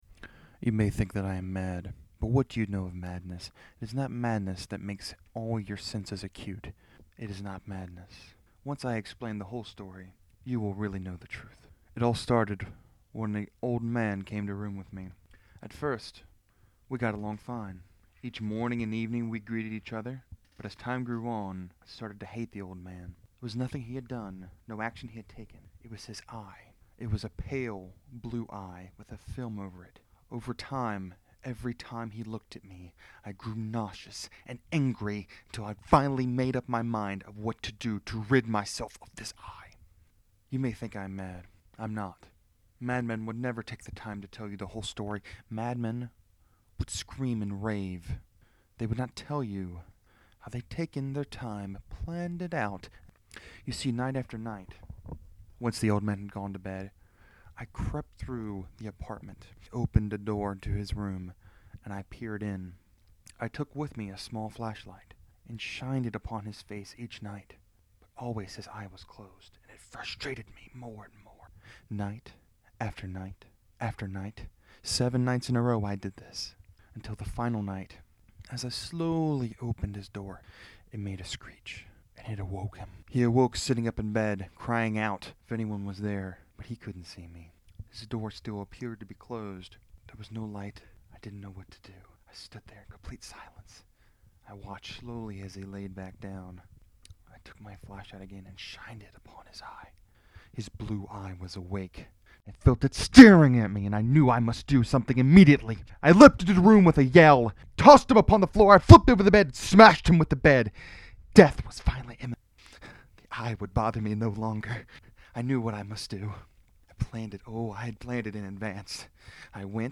Between the last episode and this one, I’ve been experimenting more with recording and voice acting.
(This may be a new trend. I’m considering doing a reading of another short story soon. Well, a remix of the story actually.)